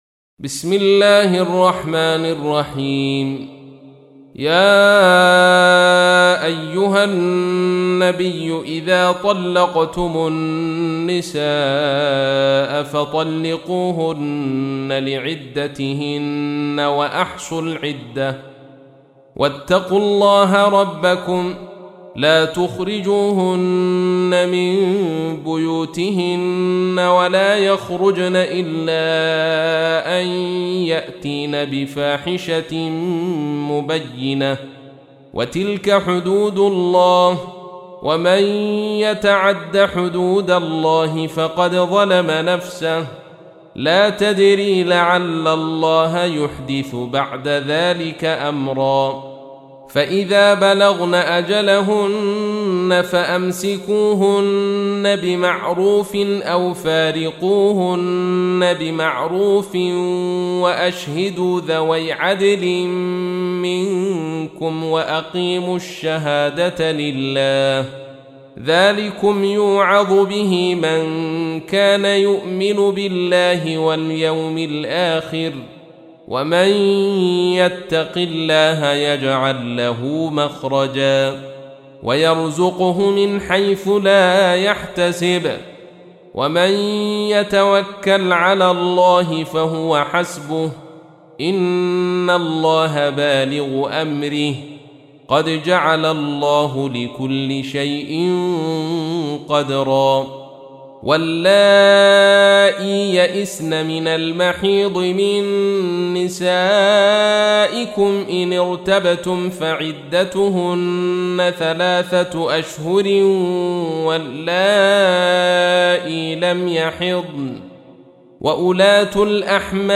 تحميل : 65. سورة الطلاق / القارئ عبد الرشيد صوفي / القرآن الكريم / موقع يا حسين